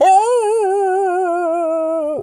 pgs/Assets/Audio/Animal_Impersonations/wolf_hurt_07.wav at master
wolf_hurt_07.wav